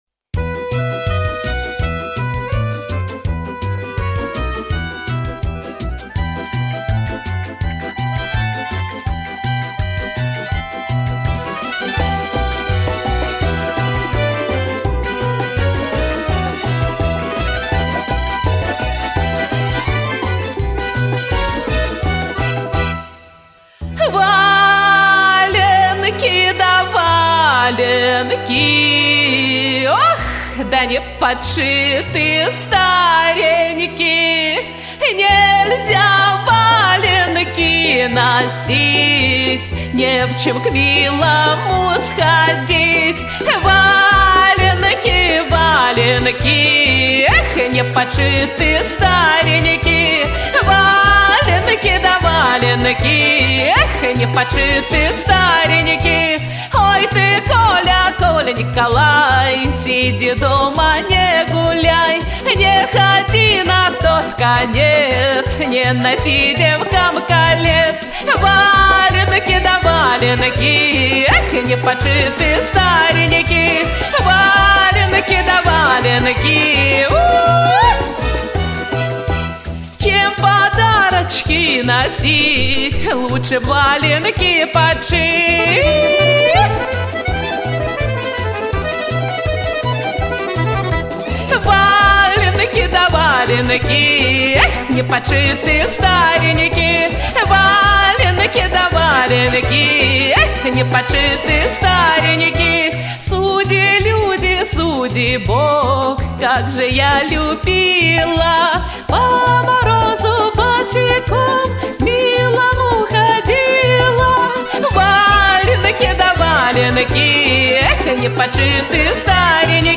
К обеих девушек голос весьма подходит к народной песне.